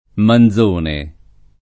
[ man z1 ne ]